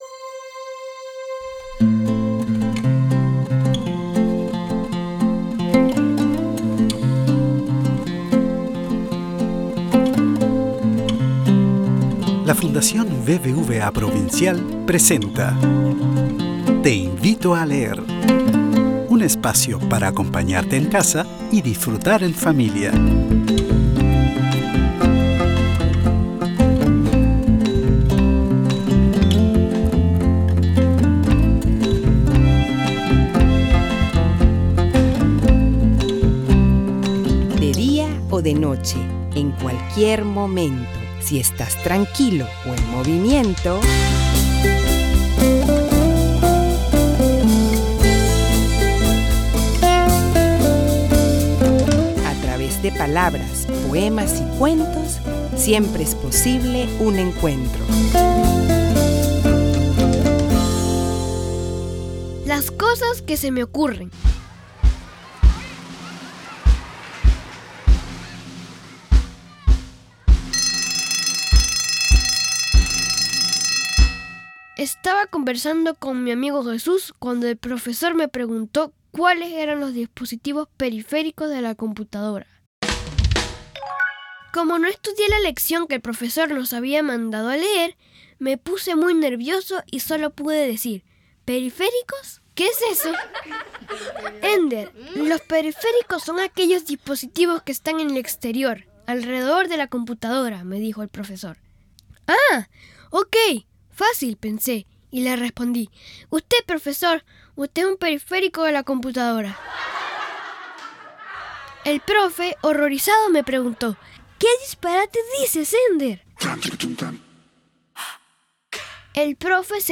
Con una musicalidad y los efectos especiales, este relato nos sumerge en los pensamientos de un niño con una gran...